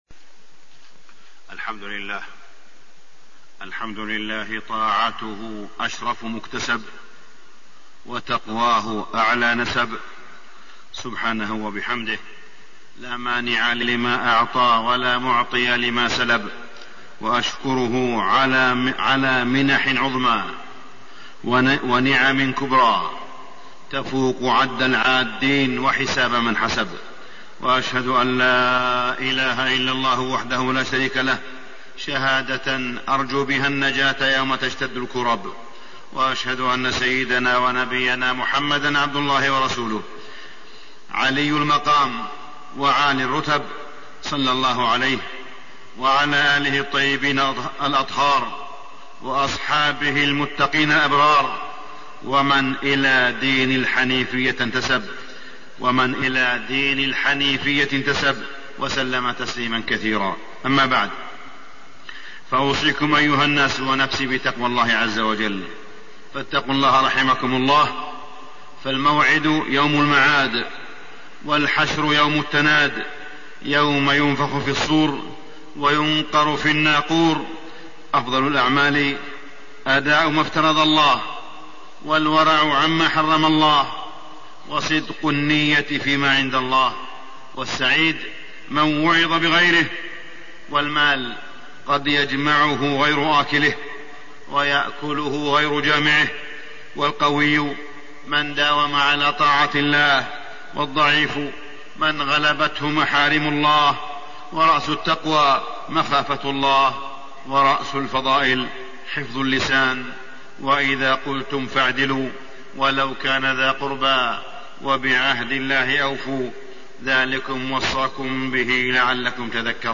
تاريخ النشر ٢١ ذو القعدة ١٤٣١ هـ المكان: المسجد الحرام الشيخ: فضيلة الشيخ د. أسامة بن عبدالله خياط فضيلة الشيخ د. أسامة بن عبدالله خياط جزاء الحج المبرور